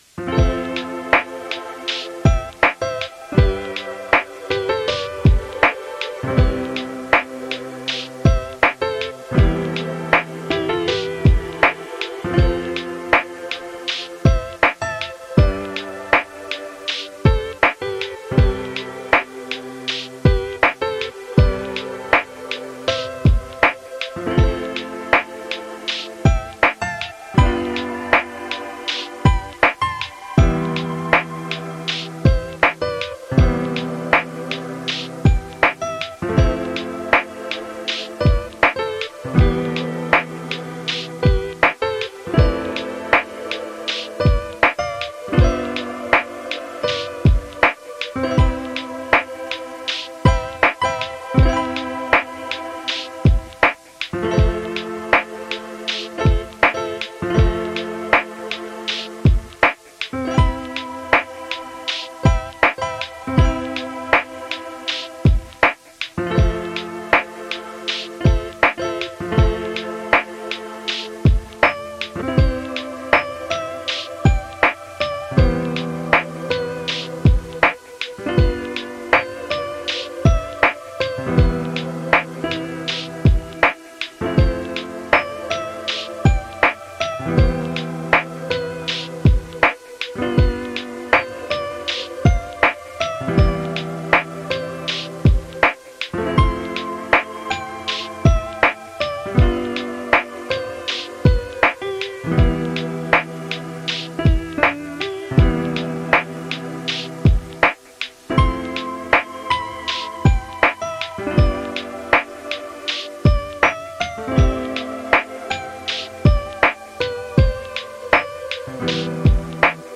ゆったりしたLo-fiです。【BPM80】